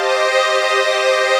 CHRDPAD060-LR.wav